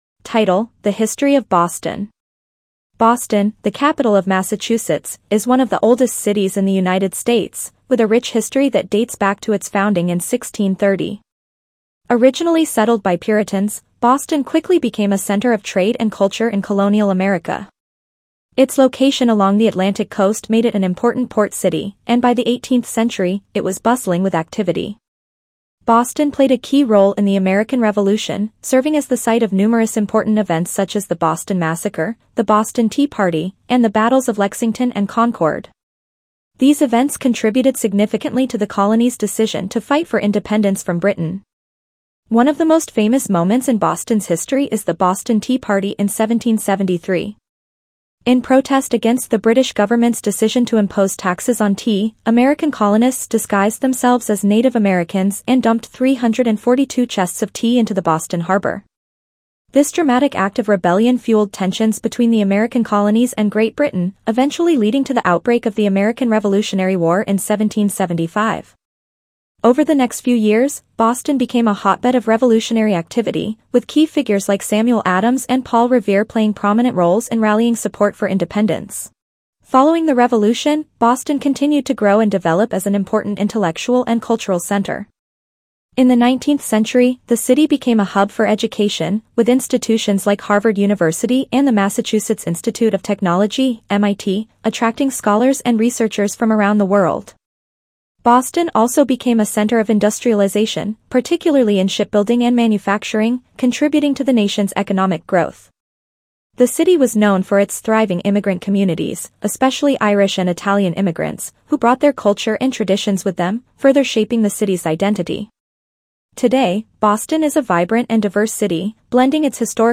Reading C1 - The History of Boston